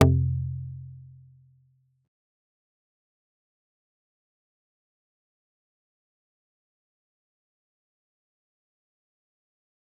G_Kalimba-F2-pp.wav